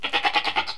goat.ogg